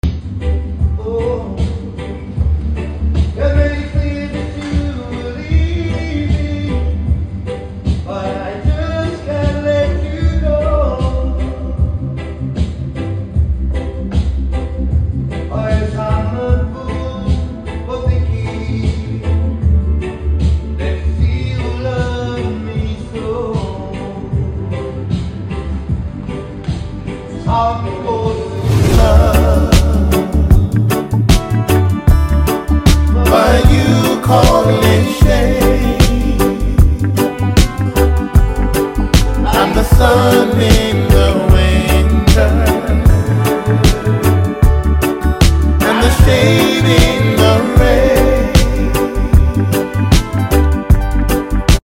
such an amazing voice.